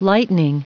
Prononciation du mot lightening en anglais (fichier audio)
Prononciation du mot : lightening